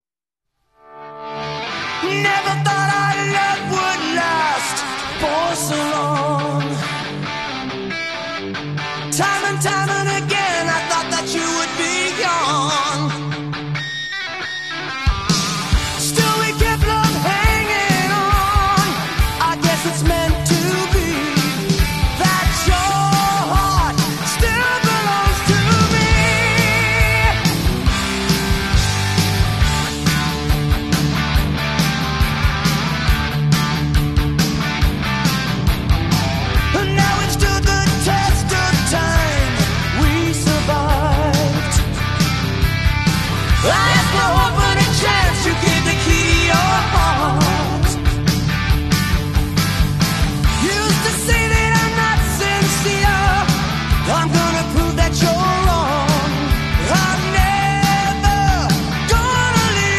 American heavy metal band
lead vocals
guitar
bass, backing vocals
drums